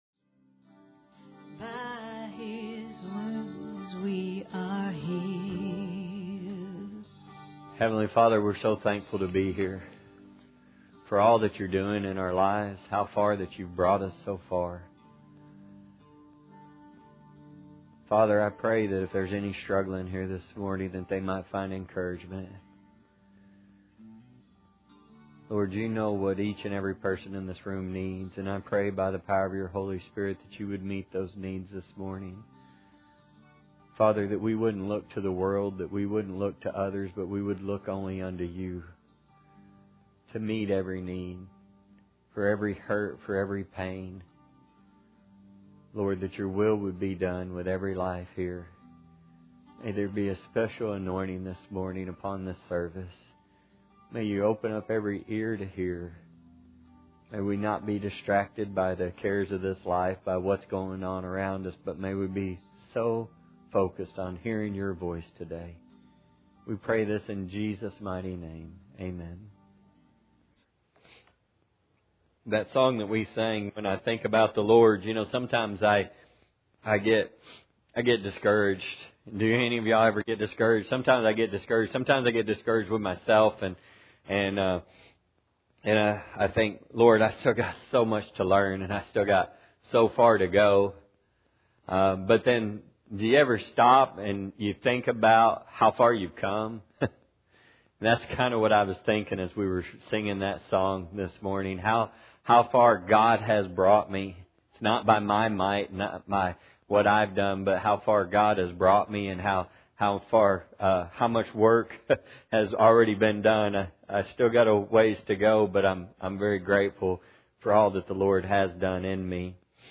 Acts 26:19-29; James 4:14; Psalm 144:4; Luke 16:27-28; Luke 15:17-24 Service Type: Sunday Morning Audio Version Below (Not intended to play with video.)